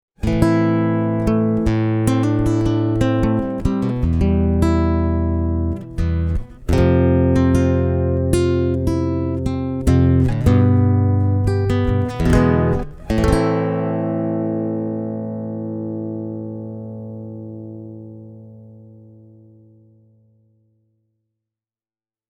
The B-Band pickup system is a fantastic choice for a nylon-string guitar, because the B-Band pickup – which works similar to an electret microphone – won’t give you any of that infamous piezo quack, which tends to make nylon-string guitars sound rather annoying.